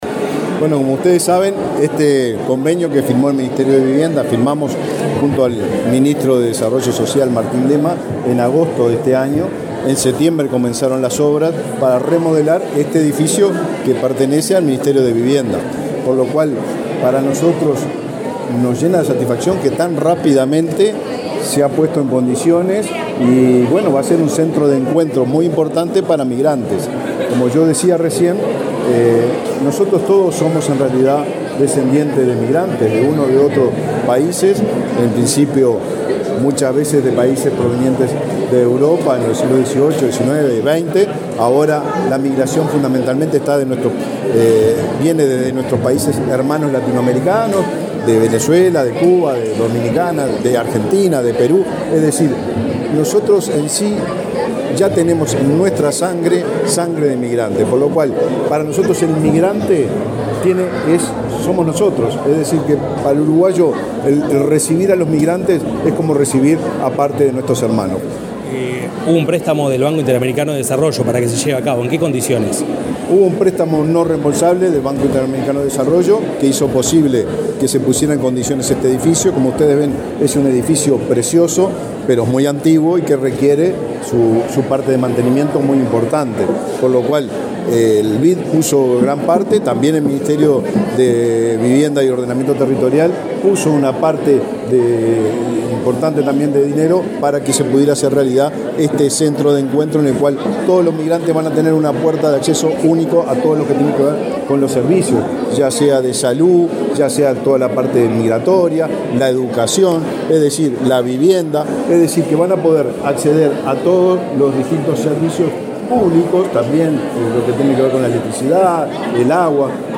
Declaraciones del ministro de Vivienda, Raúl Lozano
Declaraciones del ministro de Vivienda, Raúl Lozano 18/12/2023 Compartir Facebook X Copiar enlace WhatsApp LinkedIn El ministro de Desarrollo Social, Martín Lema, y su par de Vivienda, Raúl Lozano, participaron en el acto de recepción de la obra del Centro de Encuentro para Migrantes, en Montevideo. Luego Lozano dialogó con la prensa.